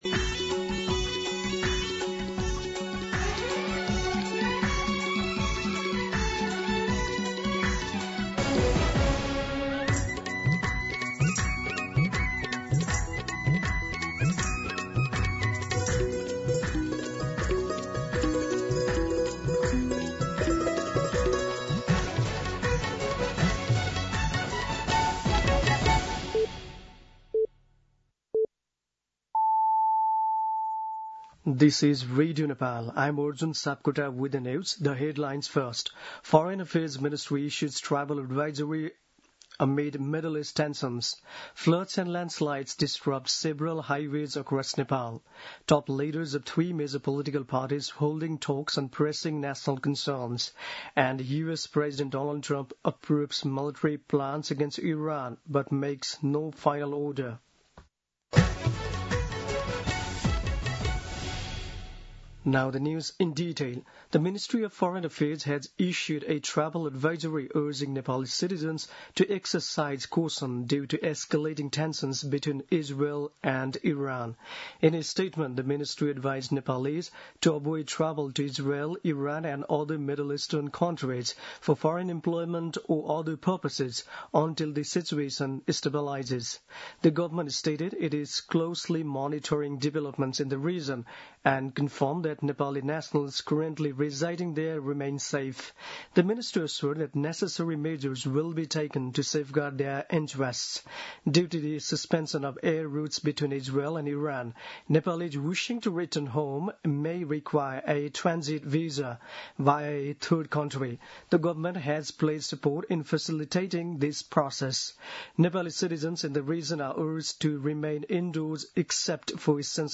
दिउँसो २ बजेको अङ्ग्रेजी समाचार : ५ असार , २०८२
2-pm-English-News-1.mp3